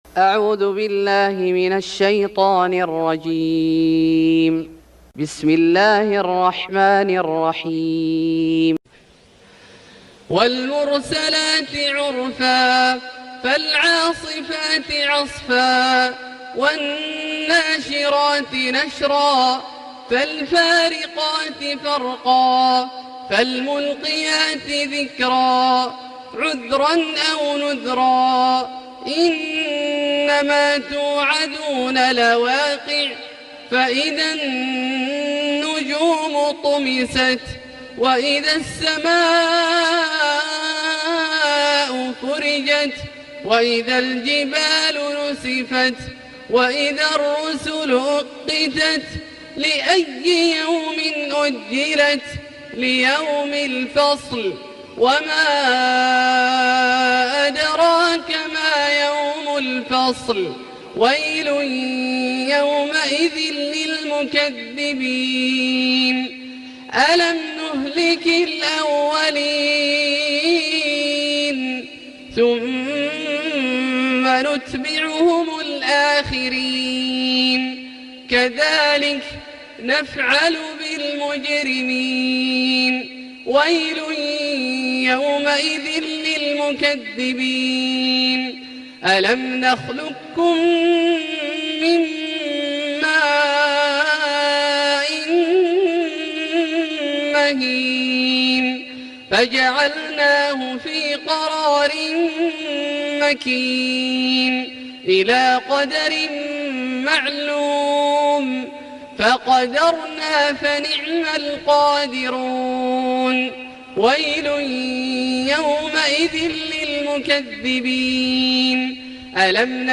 سورة المرسلات Surat Al-Mursalat > مصحف الشيخ عبدالله الجهني من الحرم المكي > المصحف - تلاوات الحرمين